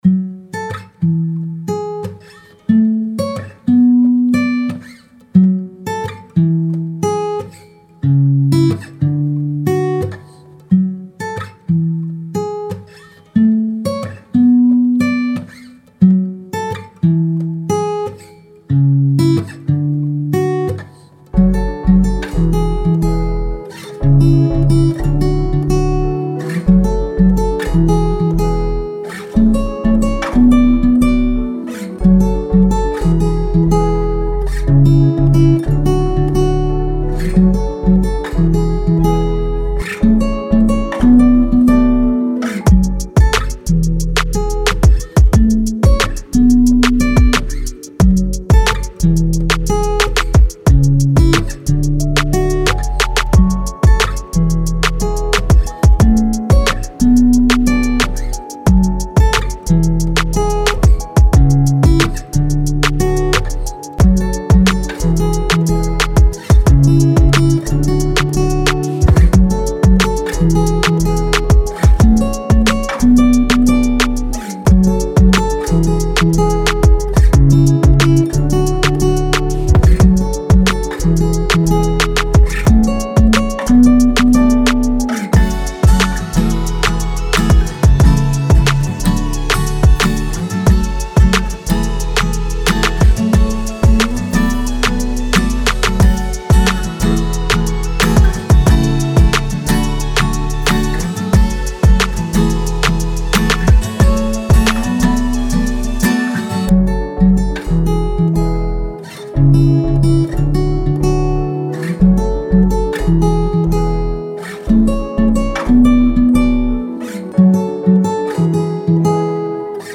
R&B, Pop, Acoustic
F# Minor